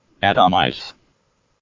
Ääntäminen
IPA : /ˈæ.tə.ˌmɑɪz/